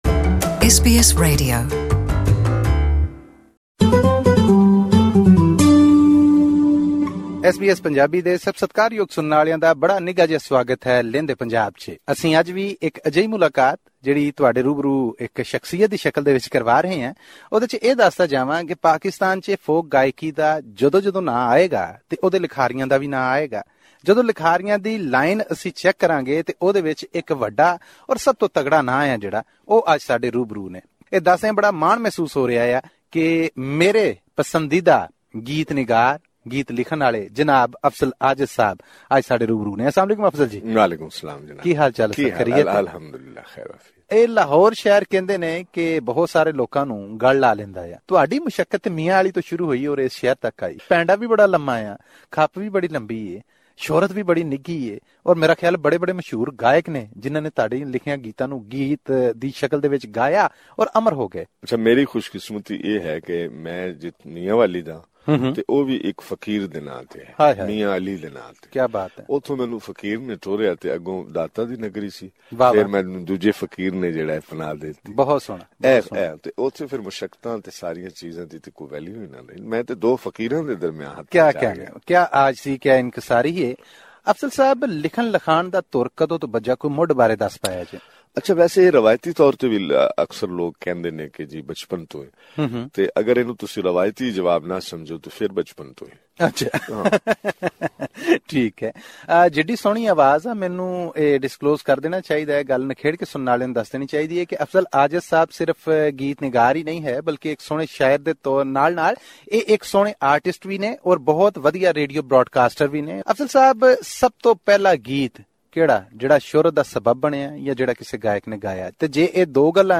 Hear the complete interview in Punjabi by clicking on the player at the top of the page.